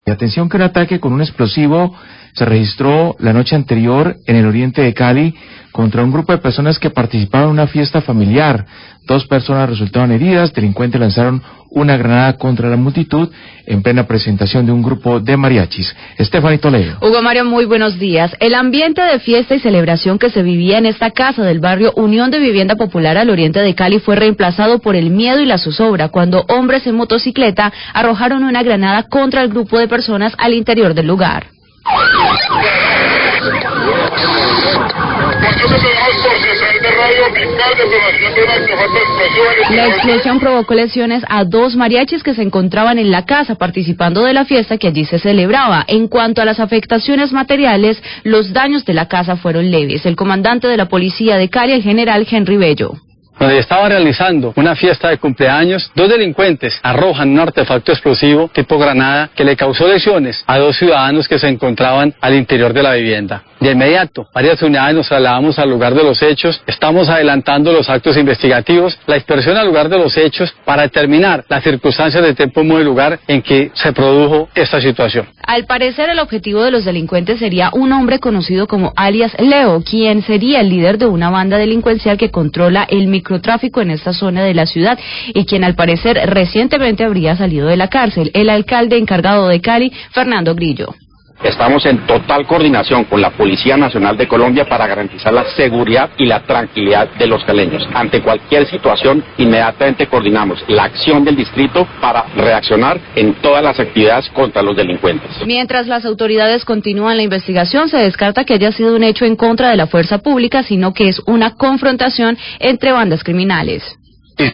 Radio
Habla el alcalde encargado Fernando Grillo.